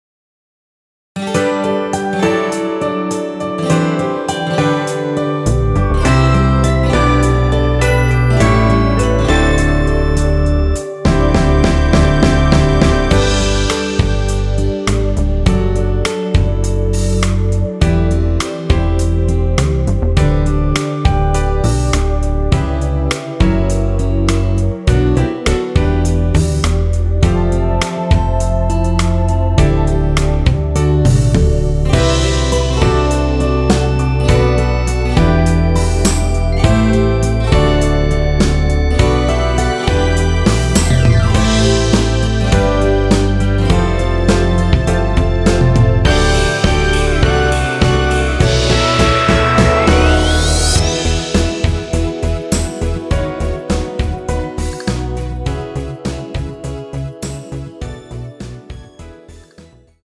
원키에서(+5)올린 MR입니다.
앞부분30초, 뒷부분30초씩 편집해서 올려 드리고 있습니다.
중간에 음이 끈어지고 다시 나오는 이유는